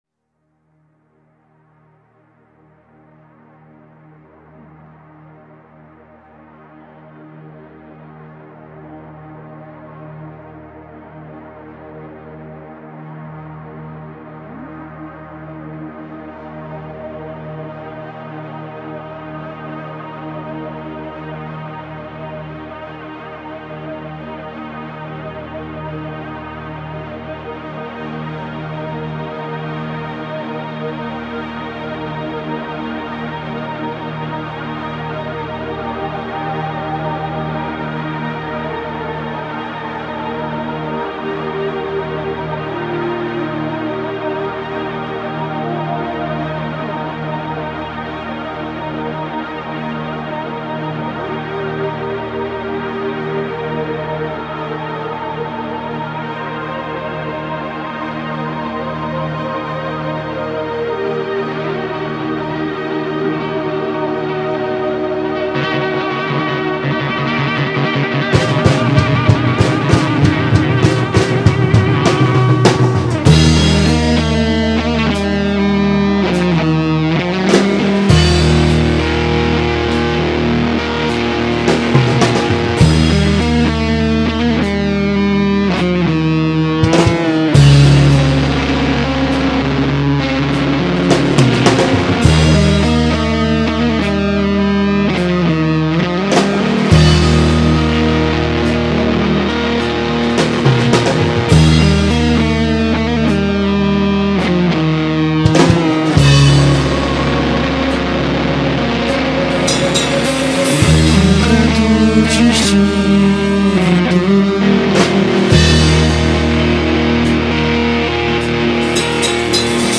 Rock Nacional
Guitarra
Bateria , Percussão
Baixo Elétrico 6